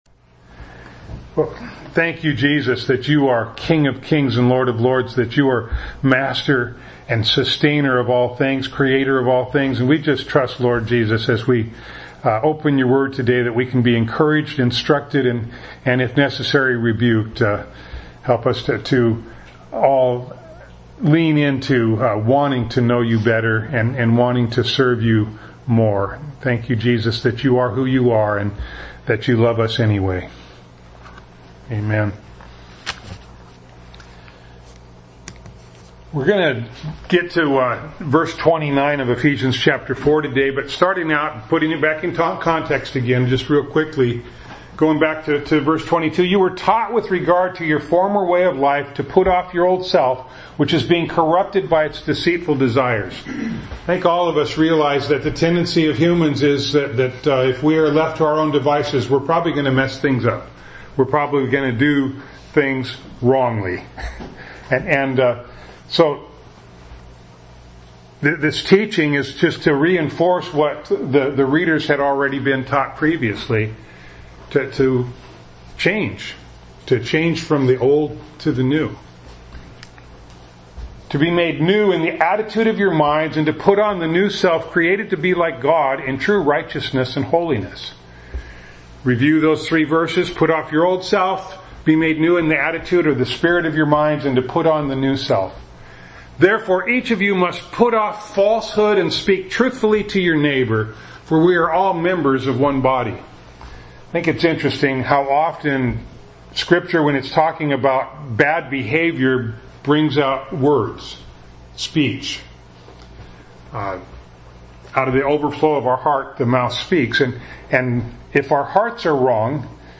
Ephesians 4:29 Service Type: Sunday Morning Bible Text